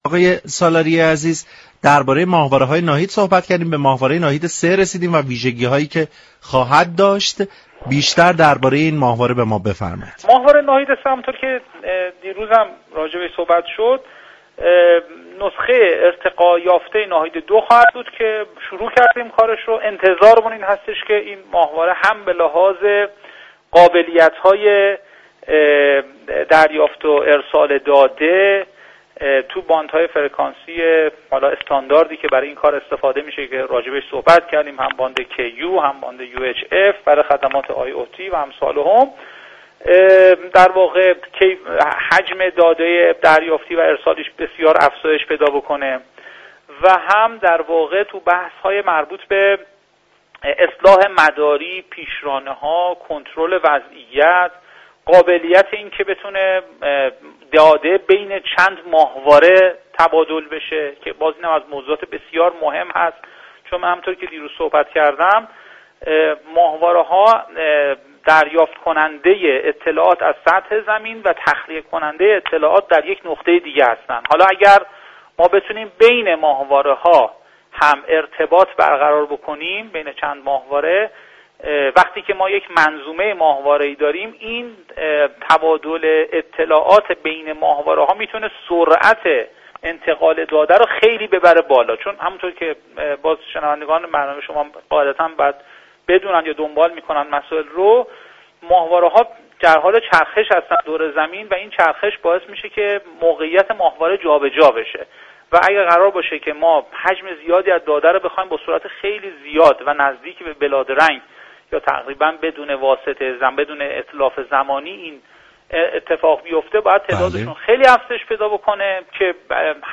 به گزارش پایگاه اطلاع رسانی رادیو تهران، حسن سالاریه معاون وزیر ارتباطات و فناوری اطلاعات و رئیس سازمان فضایی كشور در گفت و گو با «علم بهتر است» در خصوص ماهواره‌‌های مخابراتی «ناهید» اظهار داشت: ساخت ماهواره‌ مخابراتی «ناهید 3» نسخه ارتقاء یافته «ناهید 2» آغاز شده است.